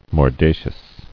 [mor·da·cious]